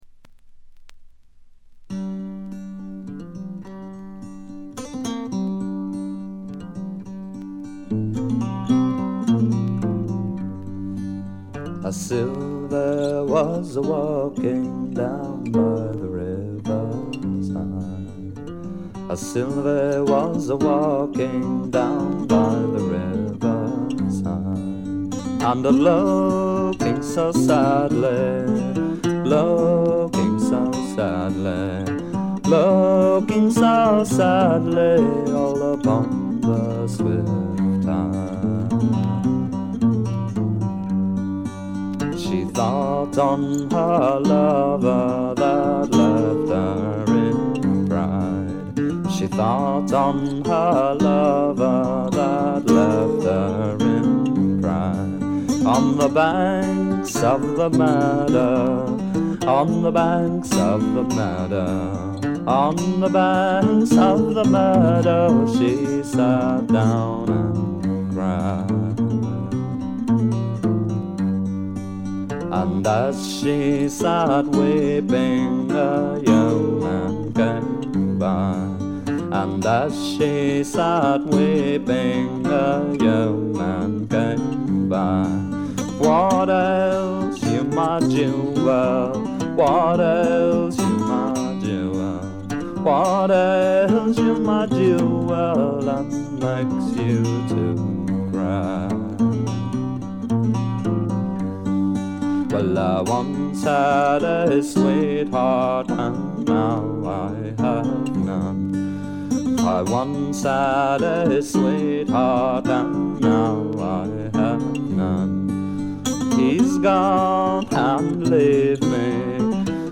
軽微なバックグラウンドノイズ、チリプチ少し。
ゲストミュージシャンは一切使わずに、自作とトラッドを味のあるヴォーカルと素晴らしいギターで表情豊かに聴かせてくれます。
試聴曲は現品からの取り込み音源です。